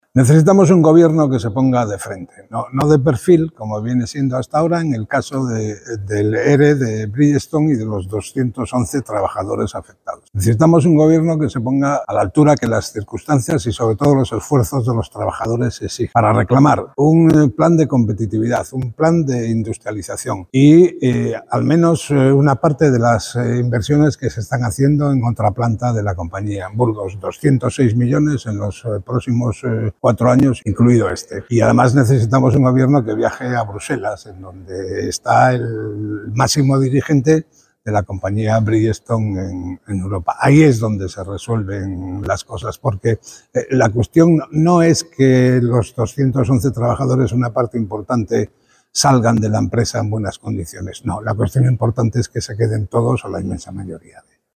Ver declaraciones de Francisco Javier López Marcano, diputado del Pertido Regionalista de Cantabria y portavoz en materia de Industria.